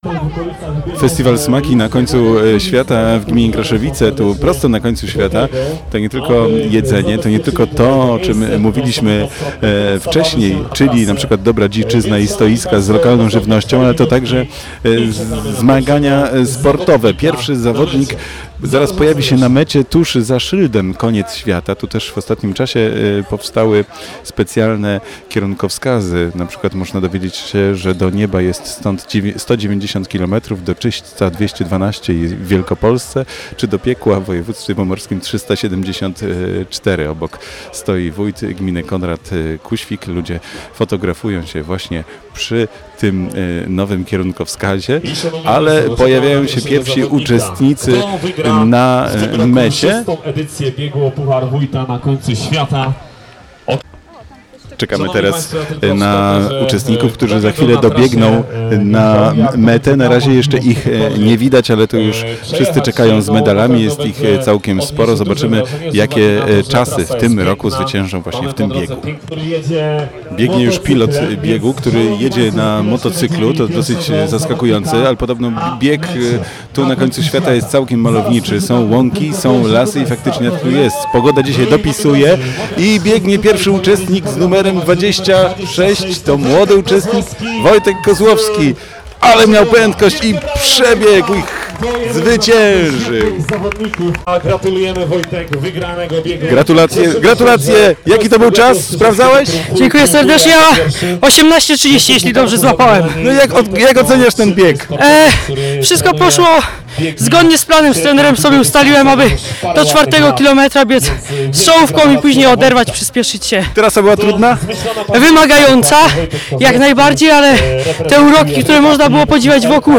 Radio Poznań miało przyjemność być częścią tego niezwykłego festiwalu, relacjonując wydarzenie na żywo.